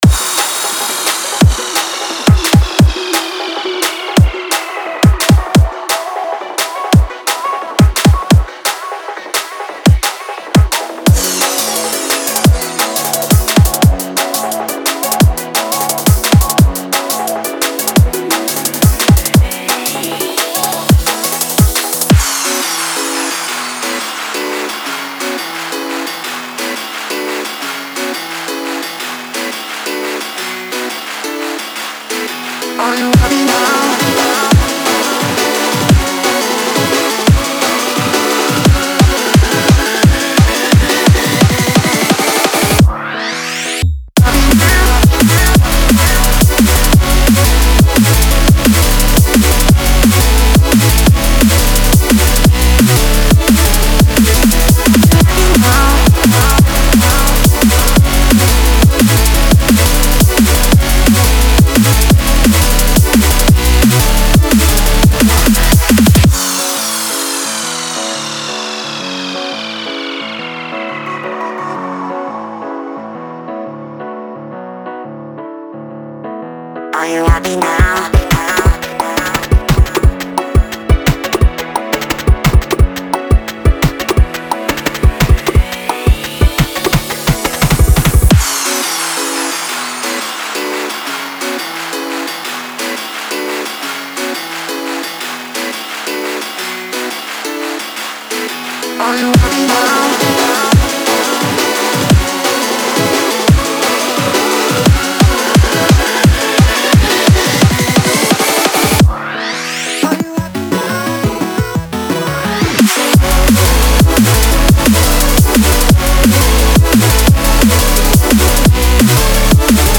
Drum & Bass, Energetic, Gloomy, Quirky, Mysterious